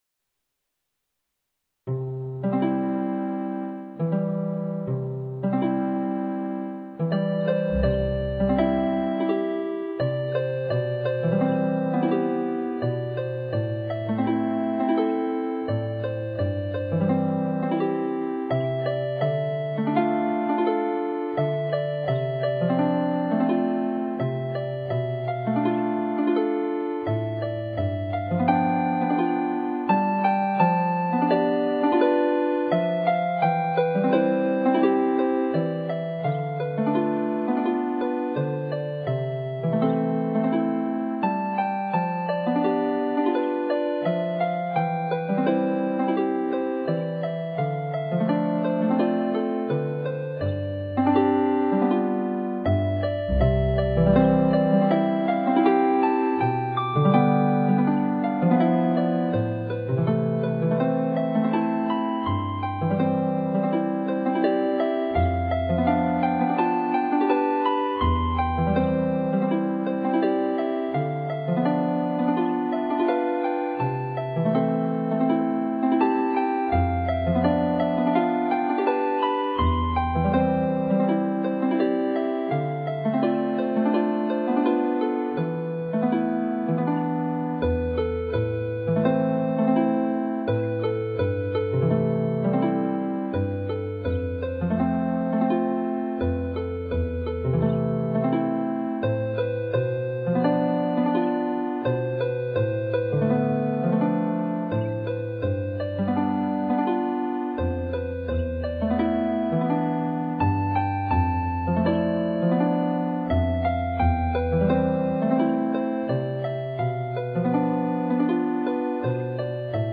A ballade for (Celtic or pedal) harp, composed in 2011.